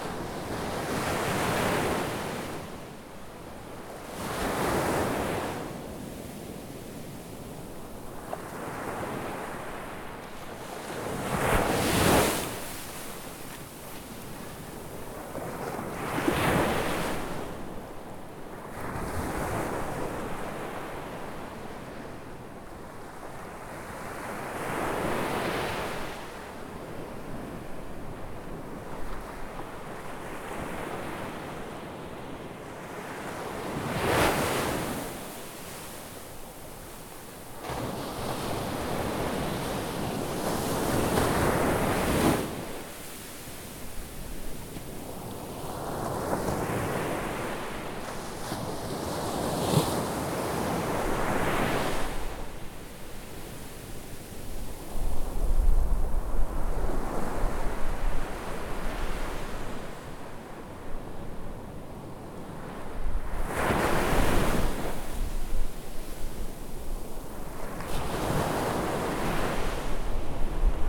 ocean-2.ogg